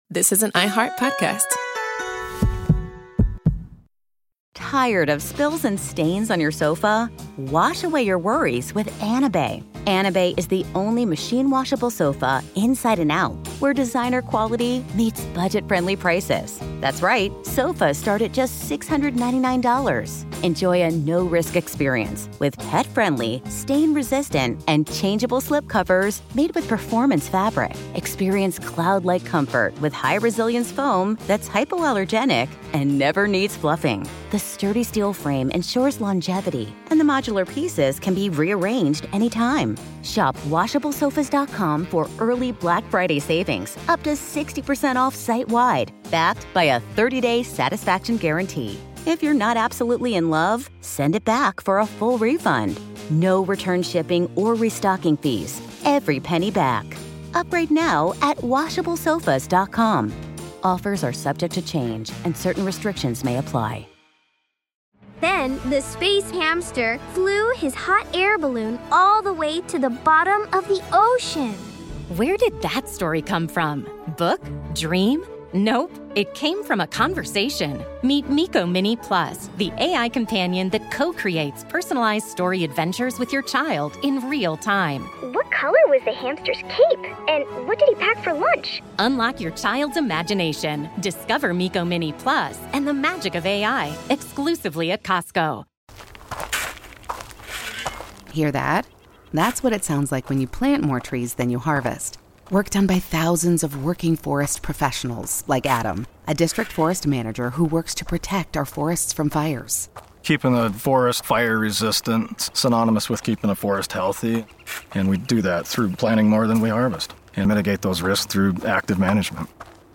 Ronald Reagan at Normandy: The D-Day Speech That Defined a Presidency
On this episode of Our American Stories, in June of 1984, President Ronald Reagan visited the cliffs of Pointe du Hoc to mark the 40th anniversary of D-Day.